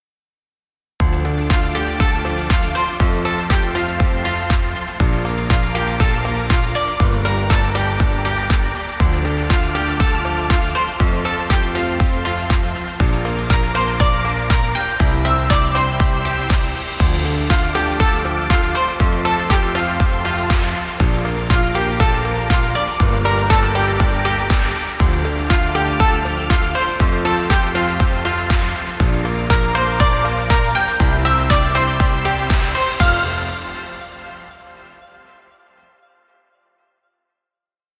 Happy Corporate music. Background music Royalty Free.